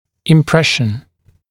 [ɪm’preʃn][им’прэшн]оттиск, слепок; впечатление